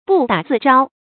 注音：ㄅㄨˋ ㄉㄚˇ ㄗㄧˋ ㄓㄠ
不打自招的讀法